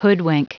Prononciation du mot hoodwink en anglais (fichier audio)
Prononciation du mot : hoodwink